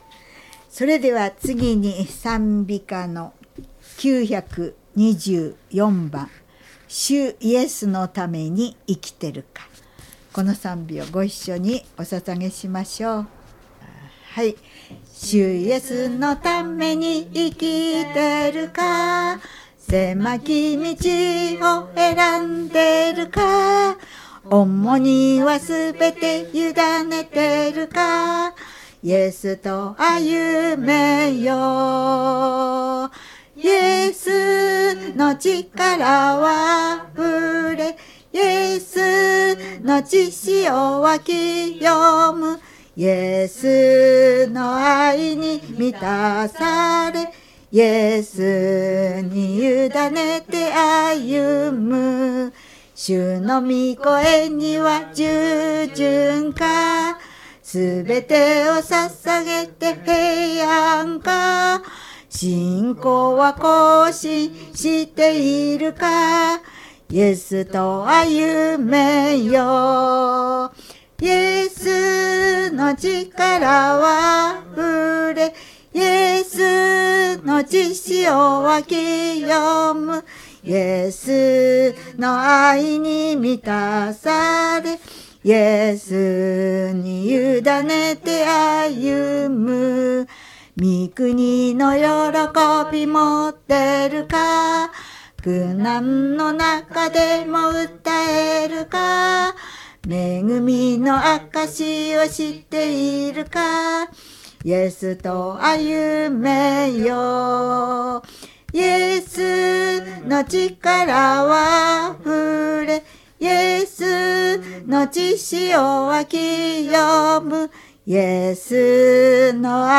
2025年 新年礼拝 「新しく力を得」 イザヤ書40章28～31（音声付）
＜礼拝メッセージ＞「新しく力を得」（一部分）